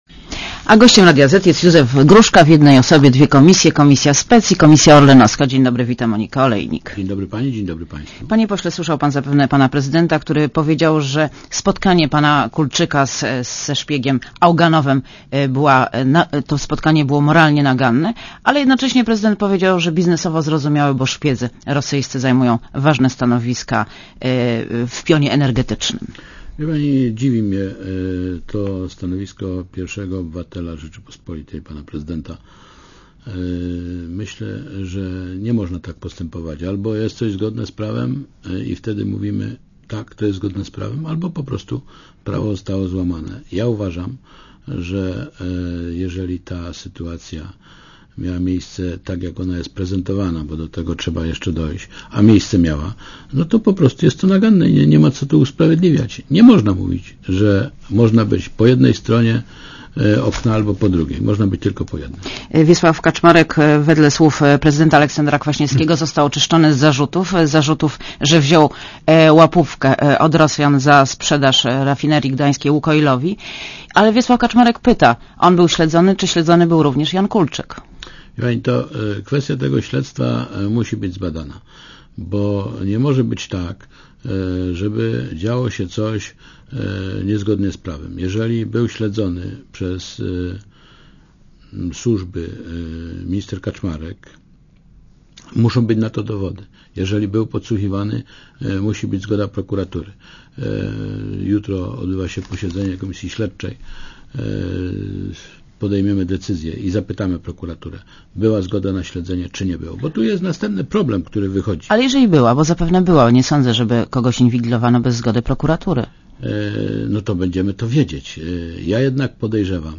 Posłuchaj wywiadu Gościem Radia Zet jest Józef Gruszka, speckomisja i komisja orlenowska w jednej osobie.